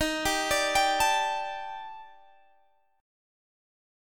EbM7b5 Chord
Listen to EbM7b5 strummed